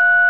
Tone3
TONE3.WAV